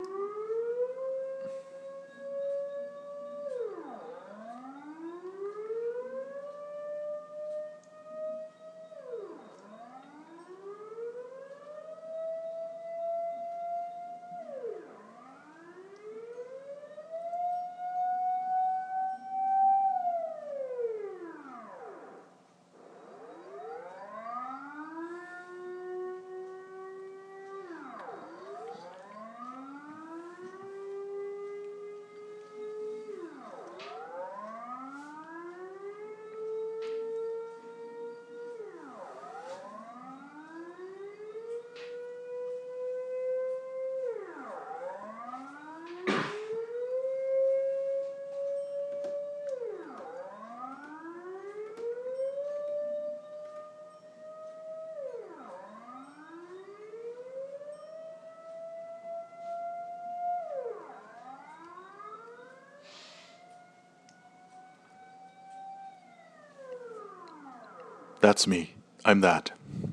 Siren.mp3